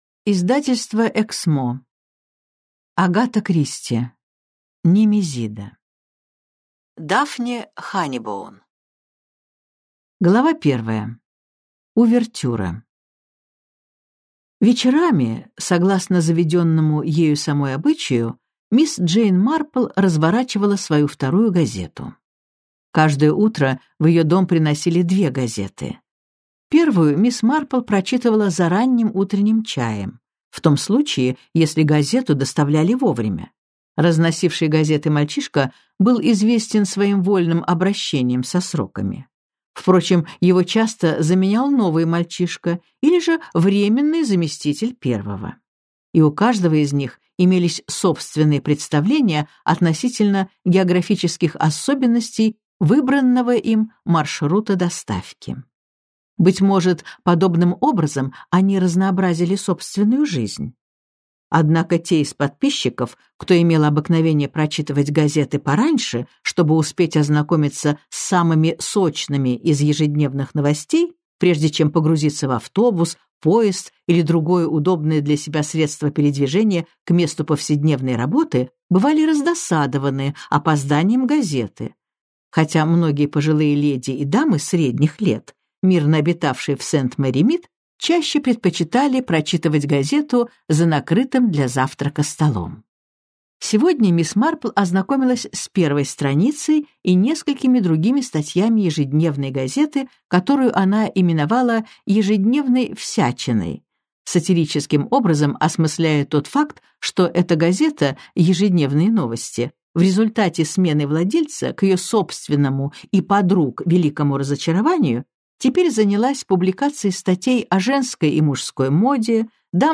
Аудиокнига Немезида - купить, скачать и слушать онлайн | КнигоПоиск